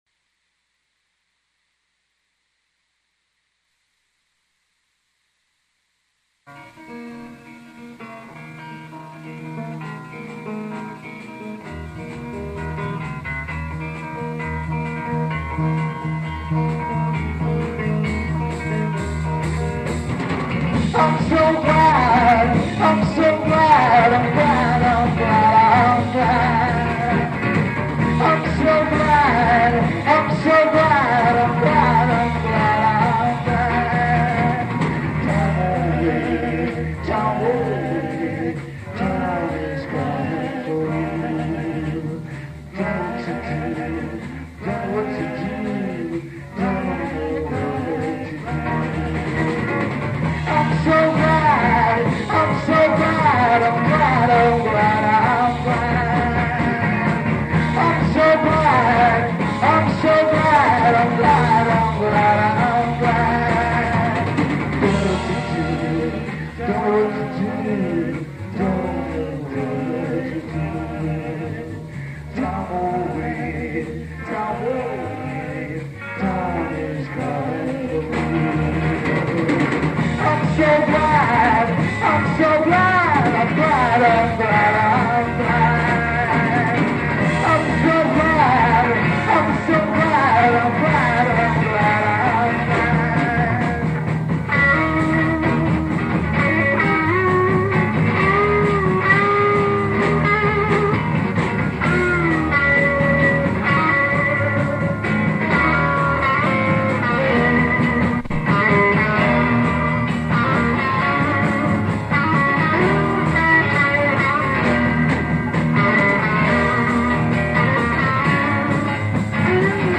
Impromptu recording
guitar
bass, me on drums